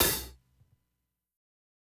Closed Hats
HIHAT_INJECT.wav